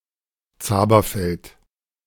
Pronounciation of Zaberfeld